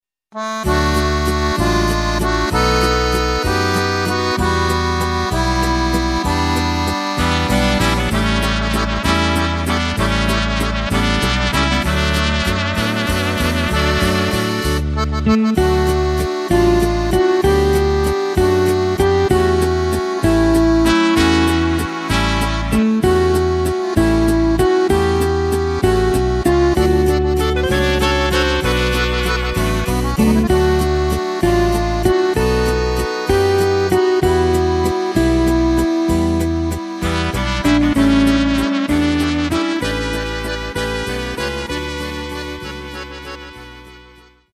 Takt:          3/4
Tempo:         193.00
Tonart:            D
Walzer aus dem Jahr 1992!